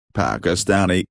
(ˌpækəˈstæni)   paquistanês (-esa)